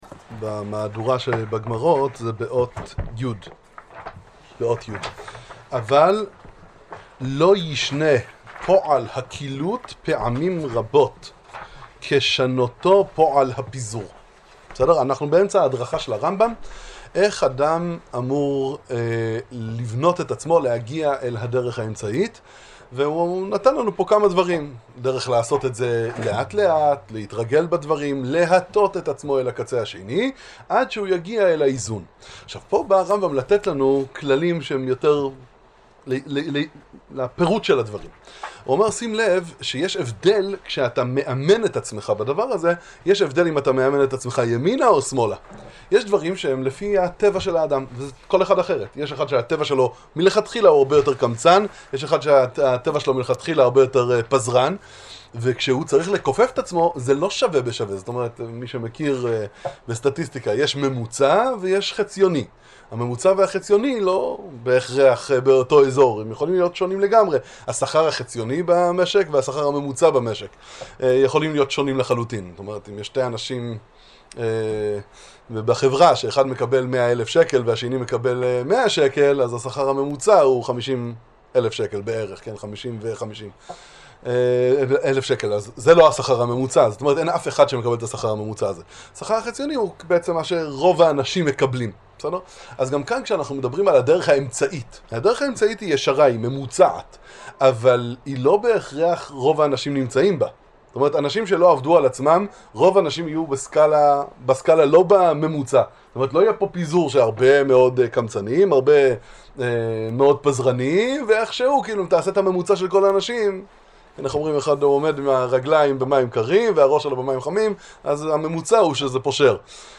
שיעור 18